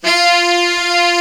Index of /90_sSampleCDs/Giga Samples Collection/Sax/SAXIBAL
TENOR HARD F.wav